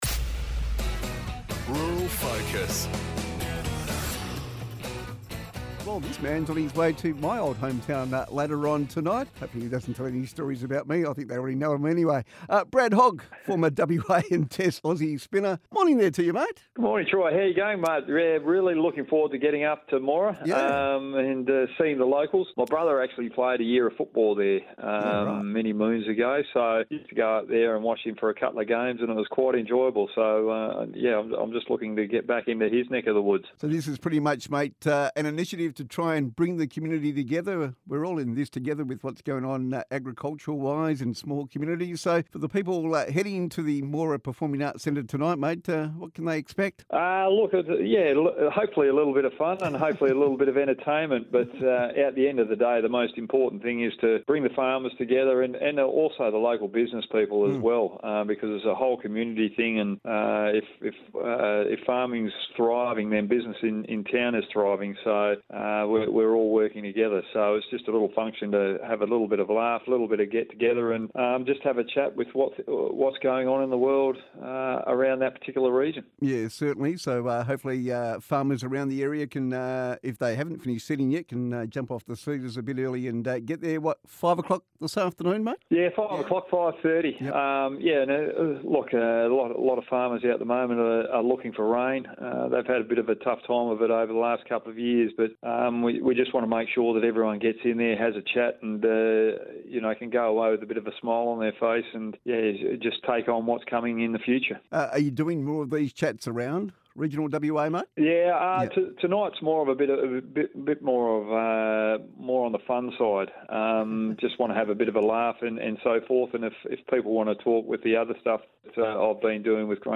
Grain Producers Australia's mental health ambassador and cricketing legend Brad Hogg spoke to Triple M's Rural Focus as he prepared to headline a community sundowner in Western Australia's northern wheatbelt town of Moora.